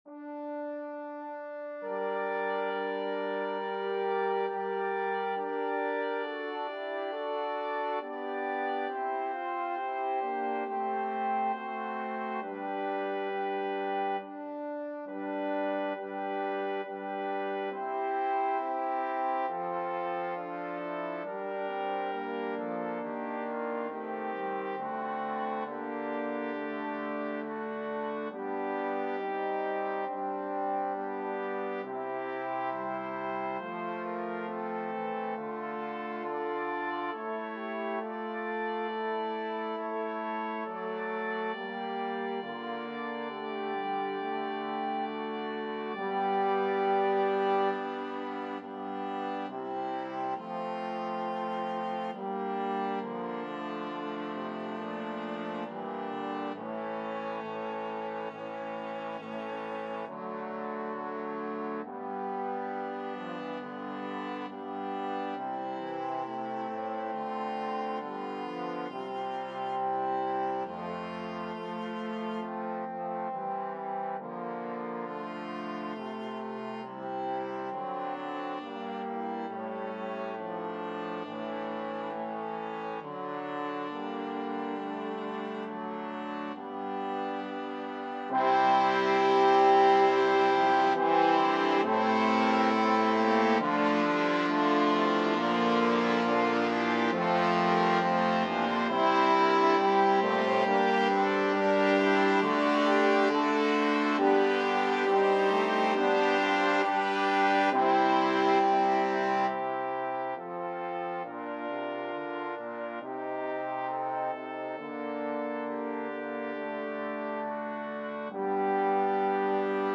Free Sheet music for Brass Ensemble
4/2 (View more 4/2 Music)
G minor (Sounding Pitch) (View more G minor Music for Brass Ensemble )
Brass Ensemble  (View more Intermediate Brass Ensemble Music)
Classical (View more Classical Brass Ensemble Music)